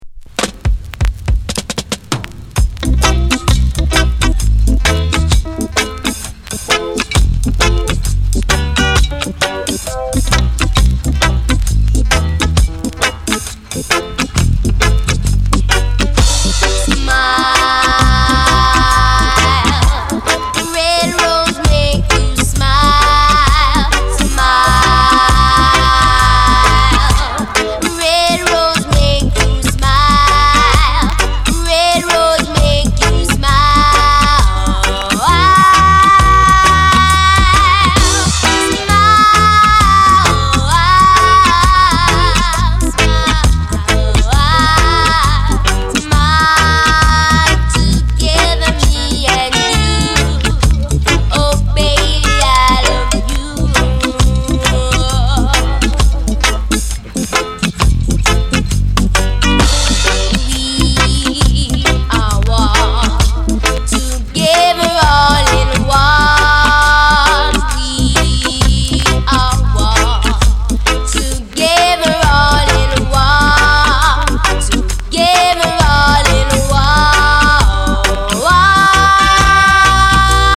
Genre:  Lovers Rock